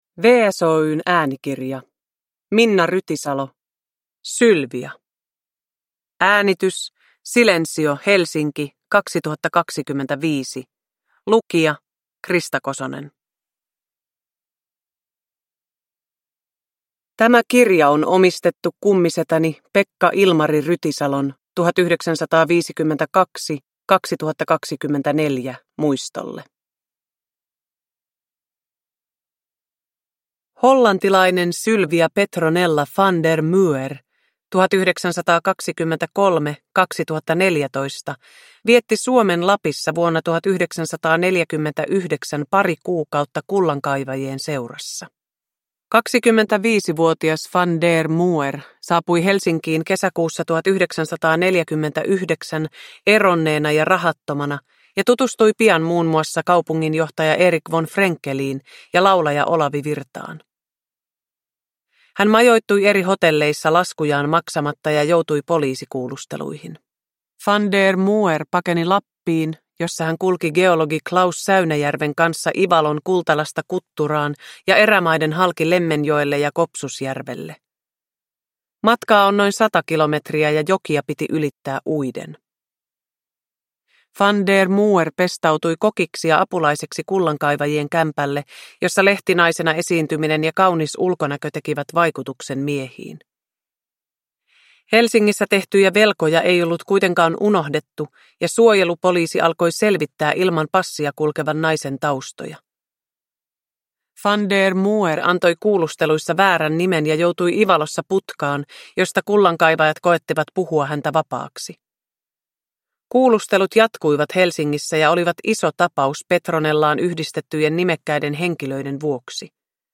Sylvia (ljudbok) av Minna Rytisalo | Bokon